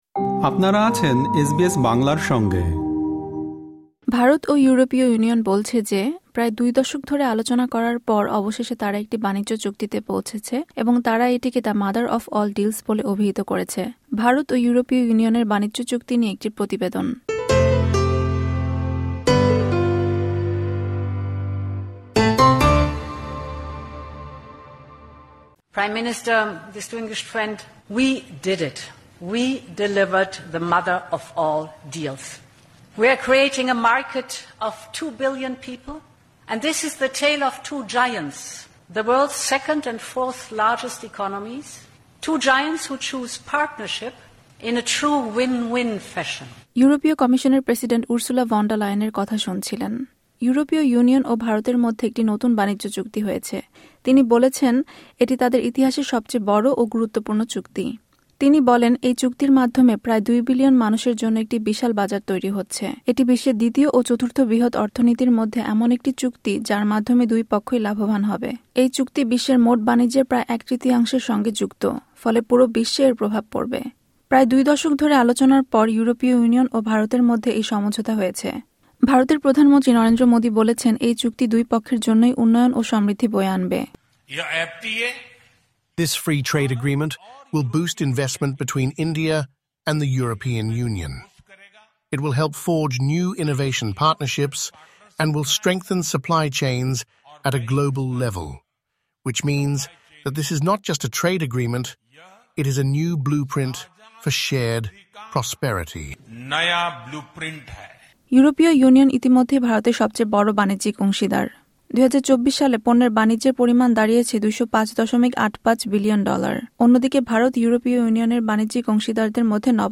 সম্পূর্ণ প্রতিবেদনটি শুনতে উপরের অডিও-প্লেয়ারটিতে ক্লিক করুন।